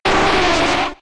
Cri de Nidoqueen K.O. dans Pokémon X et Y.